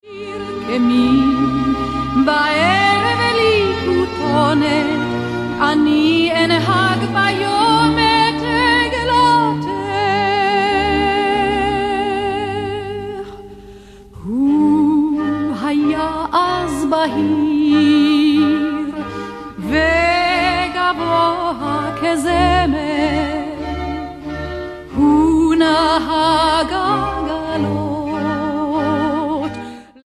Genre: Pop Rock
You will find her voice most natural and beautiful.
vocals
guitar